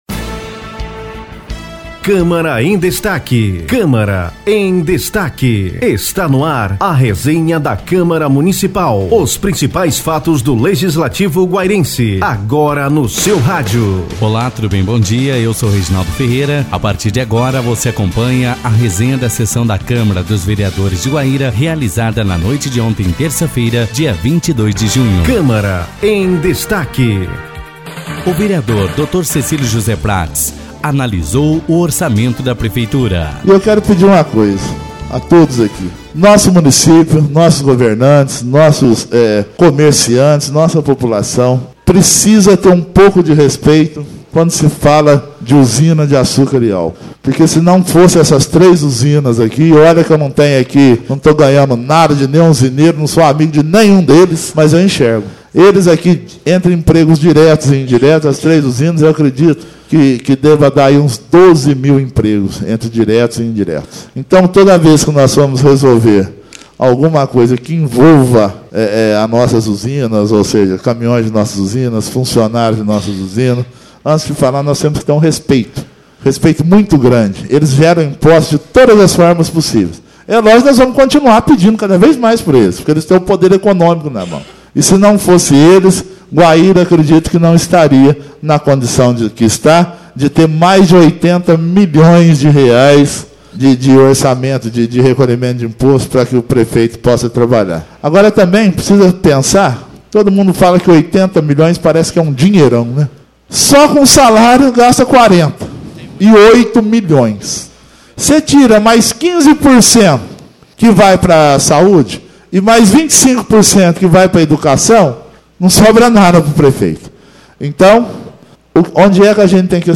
Ouça a resenha da última sessão ordinária da Câmara (22/06)